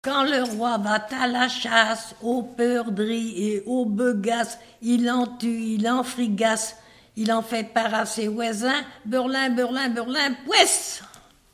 Note en tournant avec le doigt sur le genou de l'enfant
Thème : 0078 - L'enfance - Enfantines - rondes et jeux
Catégorie Pièce musicale inédite